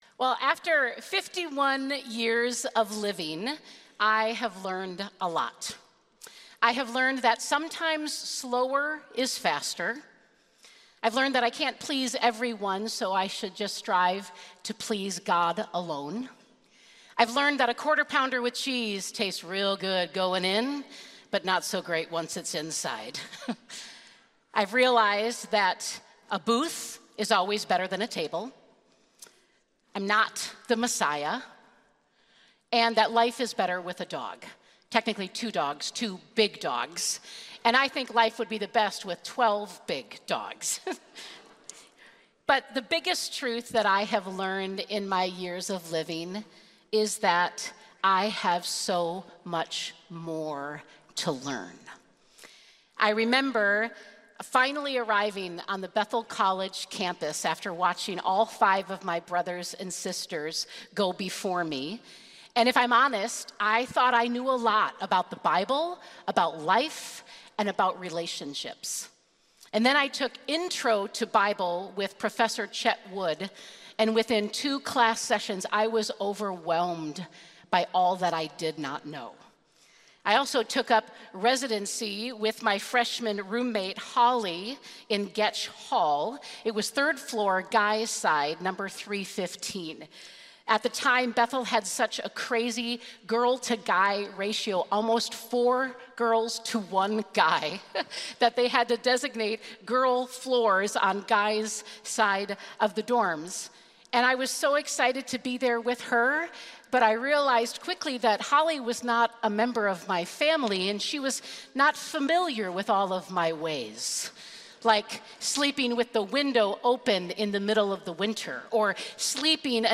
Share this Sermon Facebook Twitter Previous Best News Ever Next Sabbath is About Eden View Series